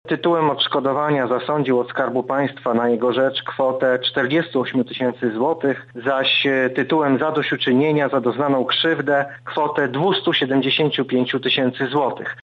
mówi sędzia Artur Ozimek, rzecznik prasowy Sądu Okręgowego w Lublinie